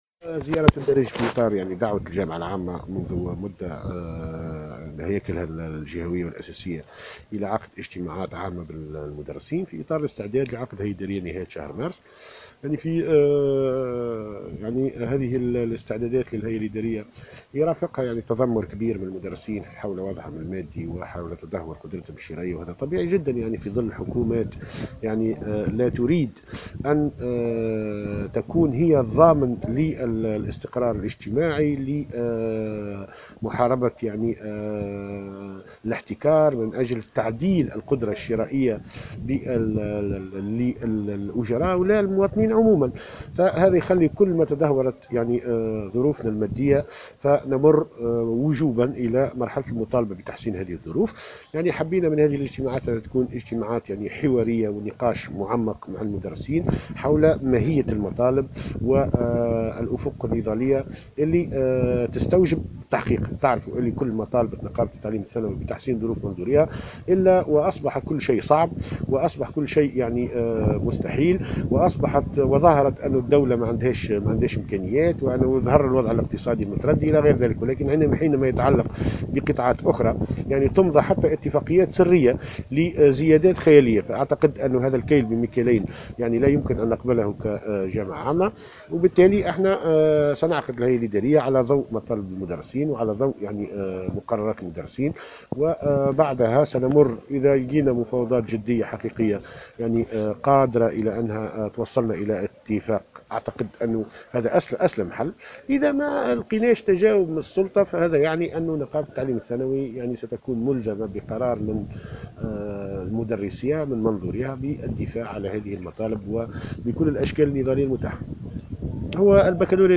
في تصريح لمراسلة الجوهرة أف أم، على هامش اجتماع نقابي انتظم اليوم الجمعة، بأحد معاهد قرمبالية، أن قرار الغاء الباكالوريا رياضة لم يكن قرارا جديدا بل كان محور تشاور مع سلطة الاشراف منذ بداية السنة الدراسية، شأنه في ذلك شأن قرار دمج الثلاثيتين لحماية المدرسين والاسرة التربوية من وباء كورونا خاصة مع ظهور السلالة الجديدة.